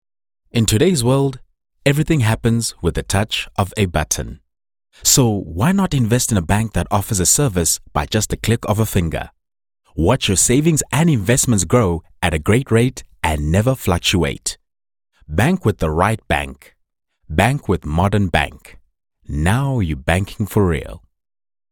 authoritative, mature, seasoned, wise
His delivery varies from bright, conversational, corporate, soft and hard sell, plus he will bring life to any script.
My demo reels